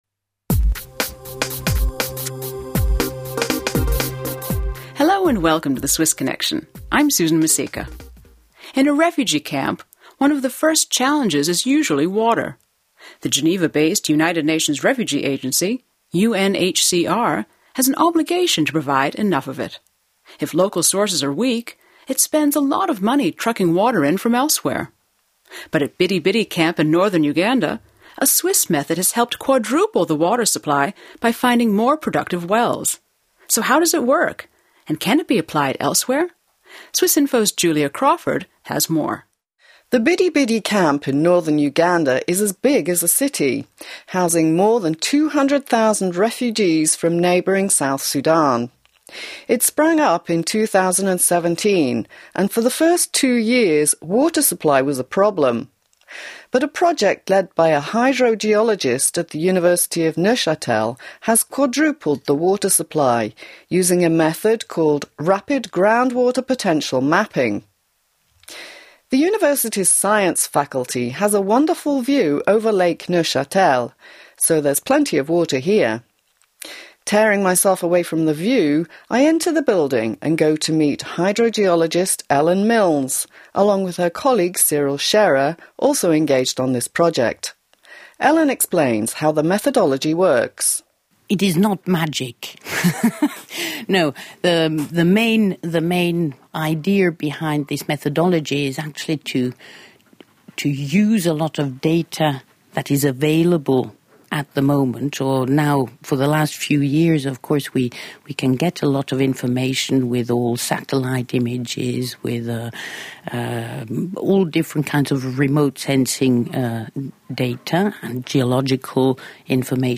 people talking about water supply